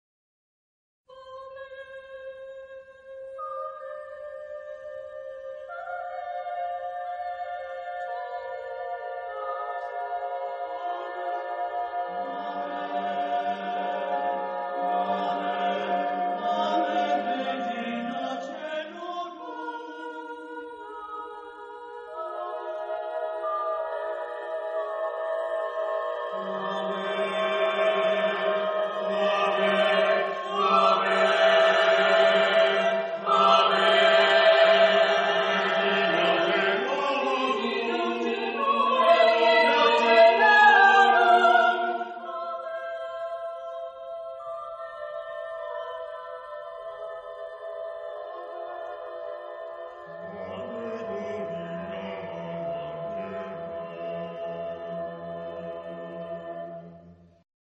Genre-Style-Forme : Sacré ; Prière
Caractère de la pièce : contrasté ; rythmé ; pieux
Type de choeur : SATB  (4 voix mixtes )